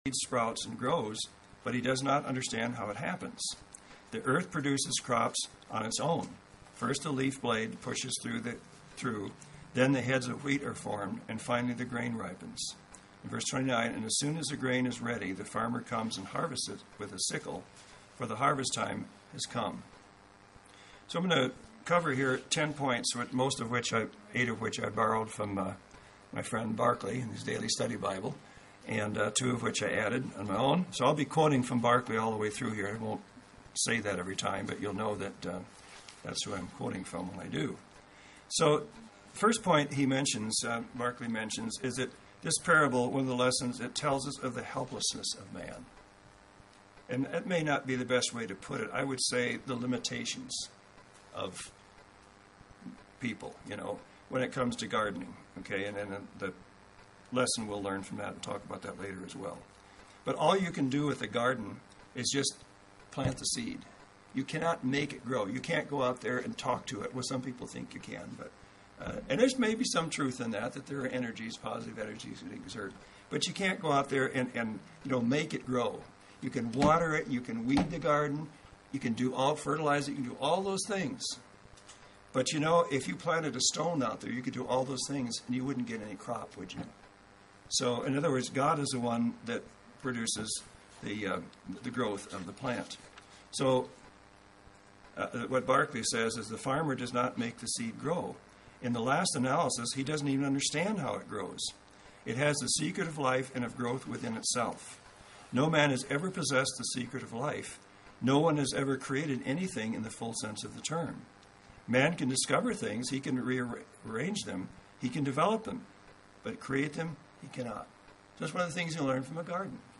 A Bible study that focuses on a short, but powerful three verse parable found in Mark 4:26-28.